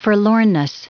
Prononciation du mot forlornness en anglais (fichier audio)
Prononciation du mot : forlornness